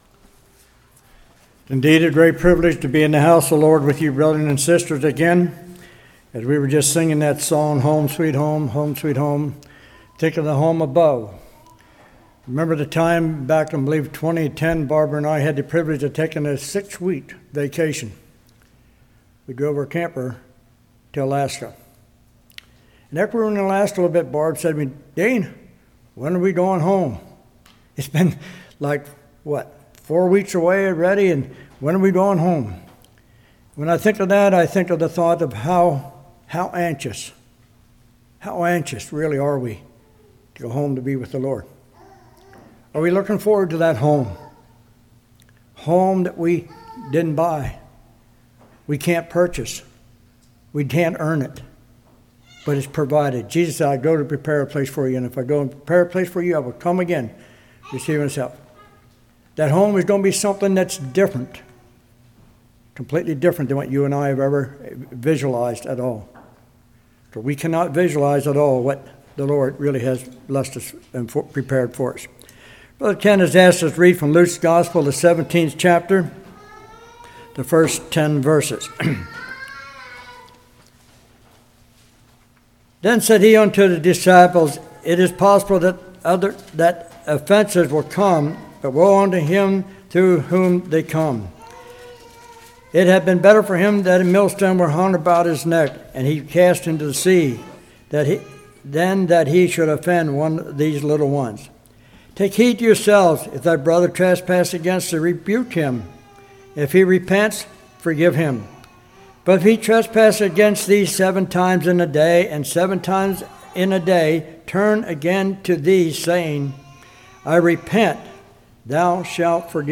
Luke 17:1-10 Service Type: Evening Avoid offending others.